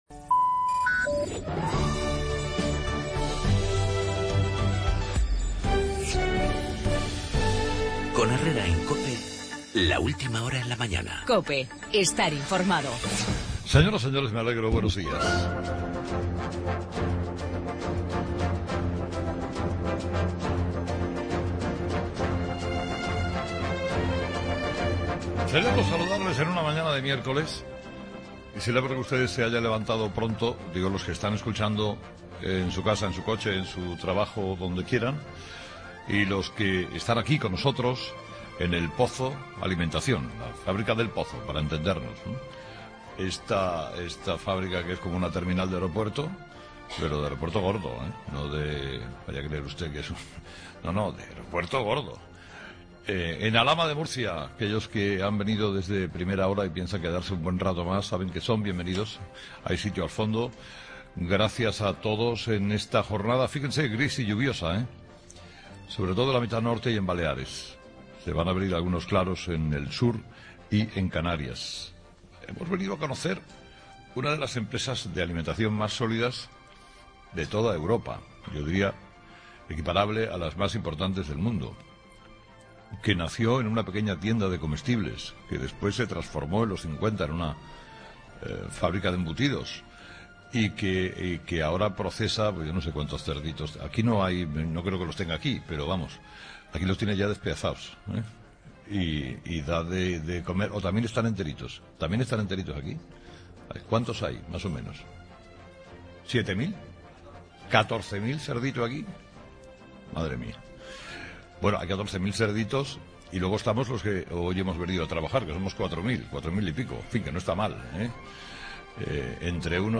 Carlos Herrera desde las instalaciones de El Pozo Alimentación en Murcia